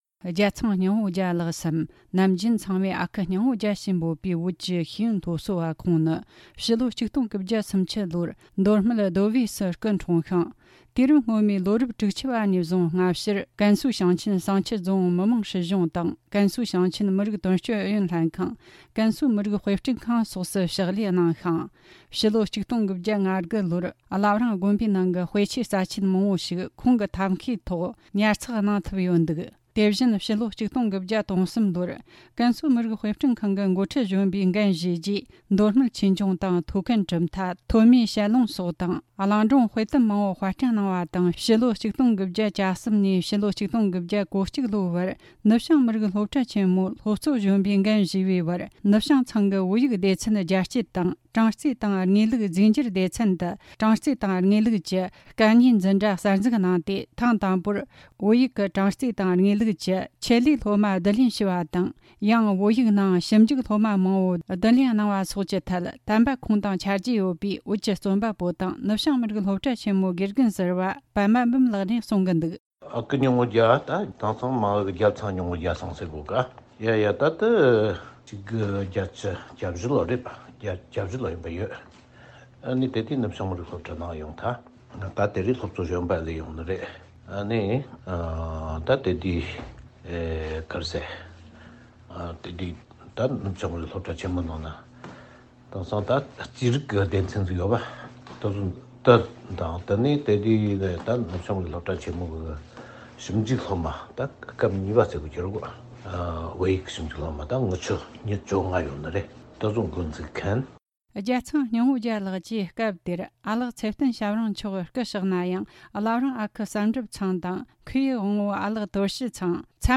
བཅར་འདྲི་དང་གནས་ཚུལ་ཕྱོགས་བསྡུས་བྱས་བར་གསན་རོགས་གནོངས།།